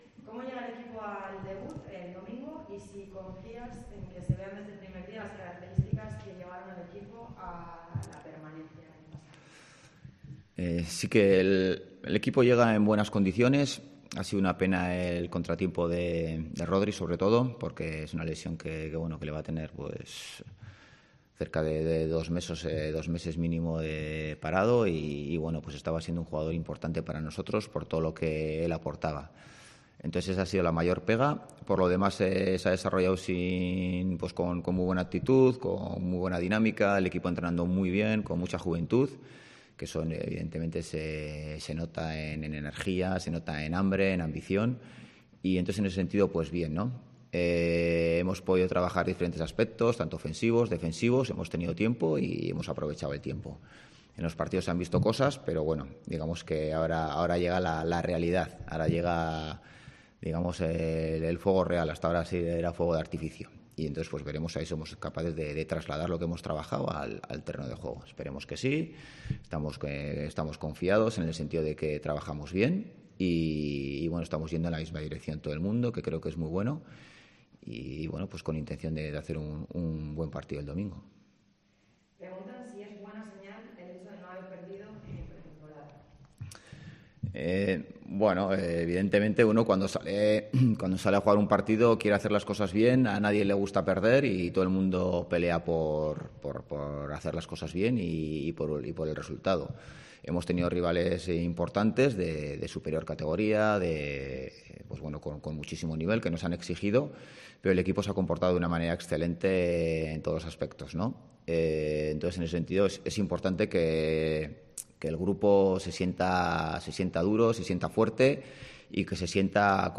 Rueda de prensa Ziganda (previa Cartagena)